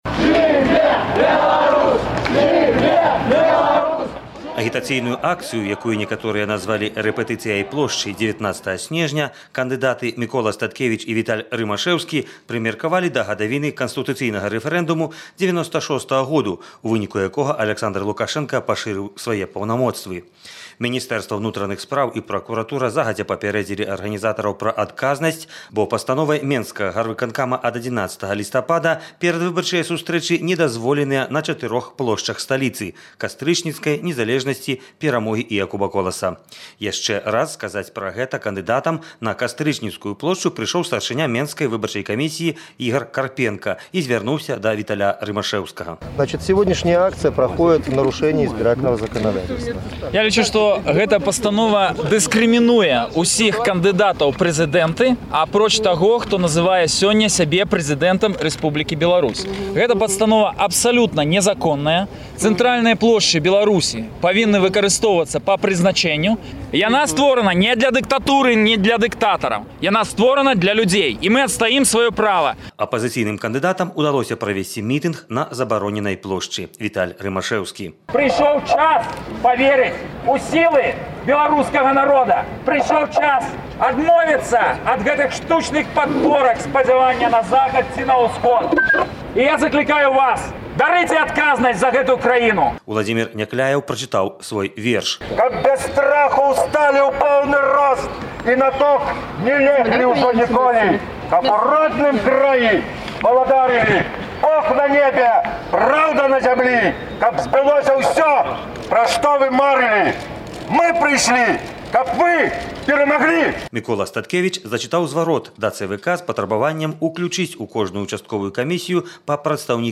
Рэпартаж